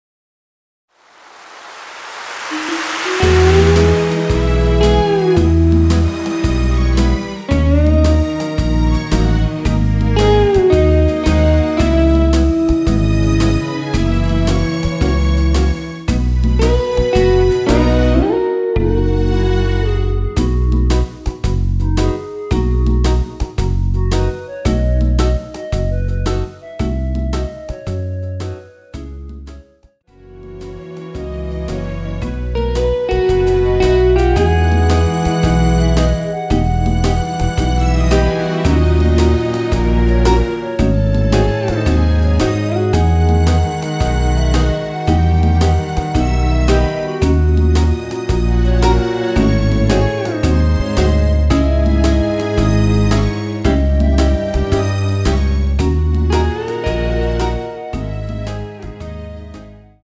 원키에서(-1)내린 멜로디 포함된 MR입니다.
◈ 곡명 옆 (-1)은 반음 내림, (+1)은 반음 올림 입니다.
앞부분30초, 뒷부분30초씩 편집해서 올려 드리고 있습니다.
중간에 음이 끈어지고 다시 나오는 이유는